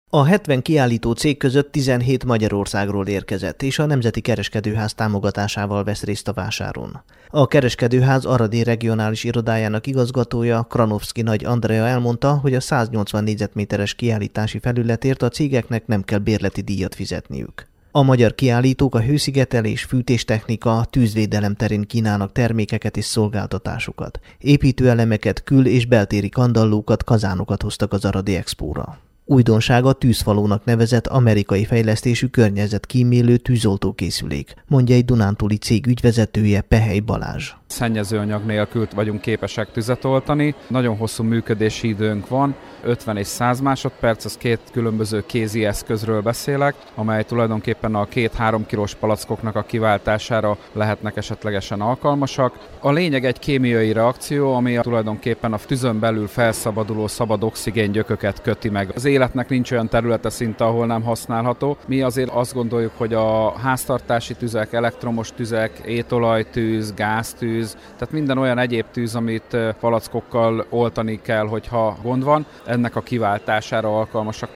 tudósítása.